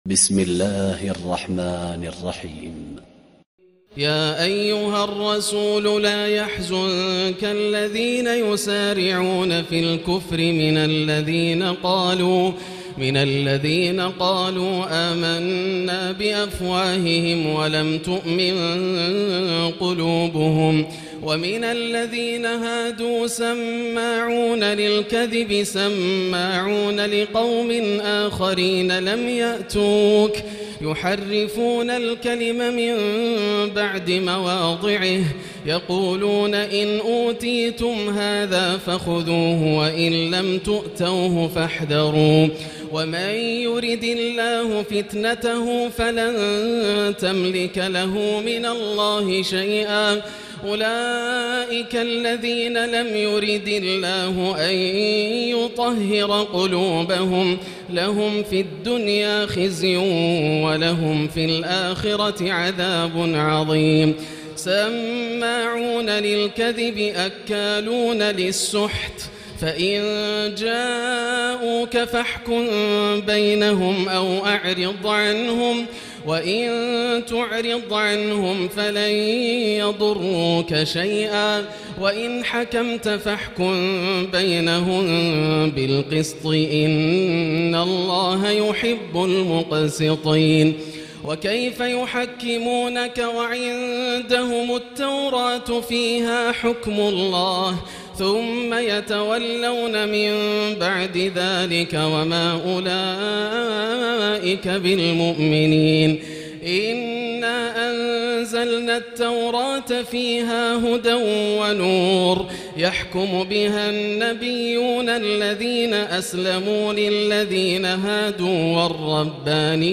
الليلة السادسة تلاوة من سورة المائدة 41-105 > الليالي الكاملة > رمضان 1439هـ > التراويح - تلاوات ياسر الدوسري